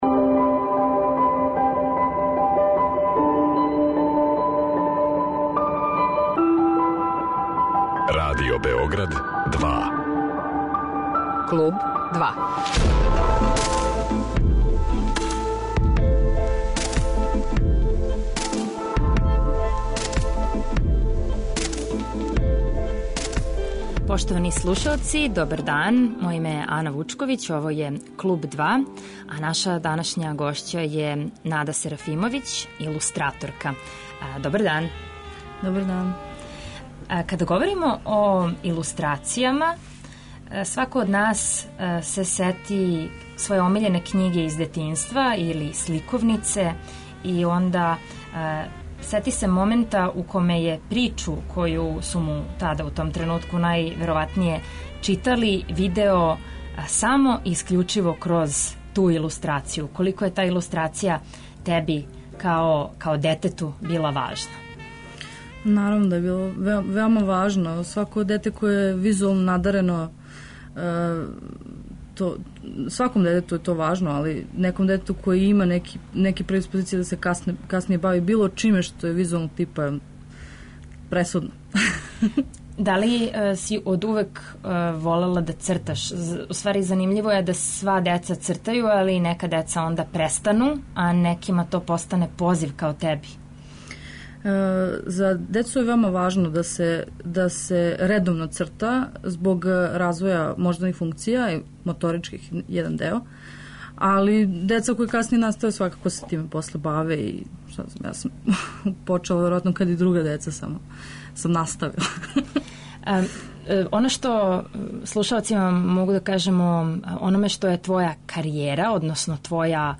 Све ово ће бити теме данашњег разговора.